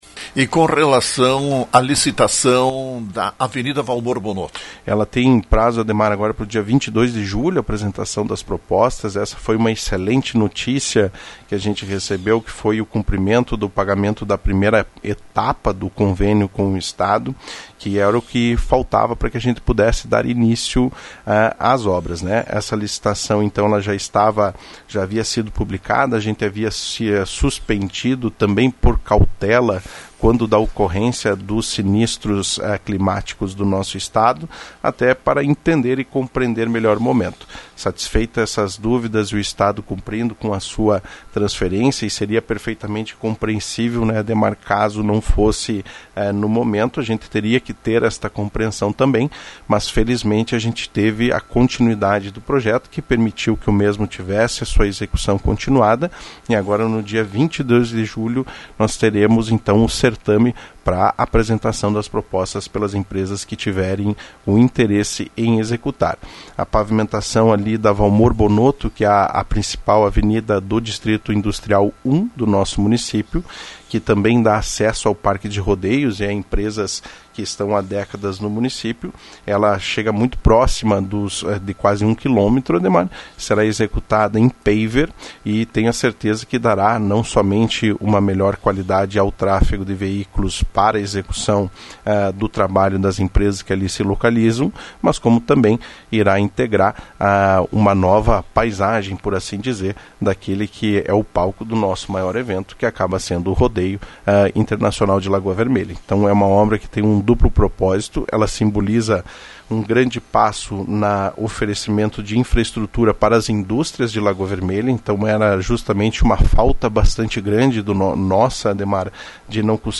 Informação transmitida pelo prefeito Gustavo Bonotto: as propostas das empresas interessadas em realizar a pavimentação da Avenida Valmor Bonotto poderão ser encaminhadas até o dia 22 de julho. Estado destinou 2 milhões de reais para essa obra.